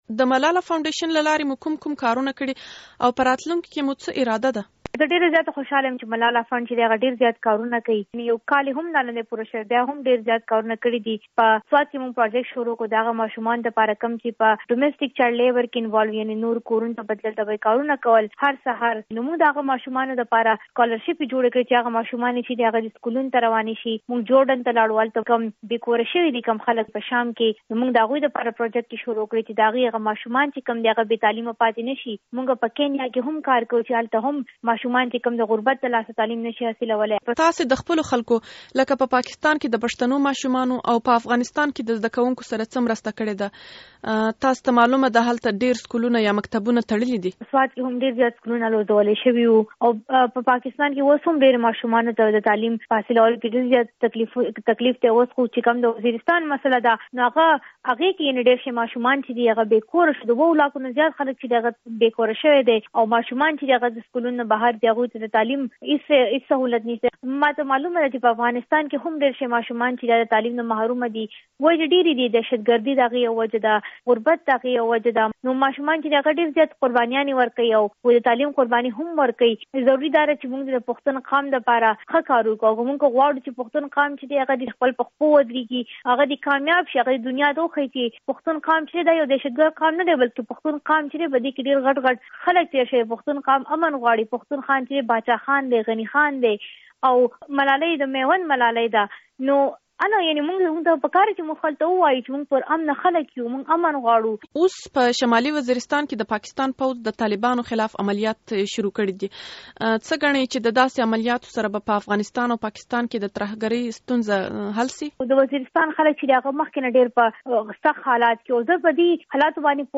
د ملالې یوسفزی سره مرکه